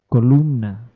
Ääntäminen
US Tuntematon aksentti: IPA : /ɹæŋk/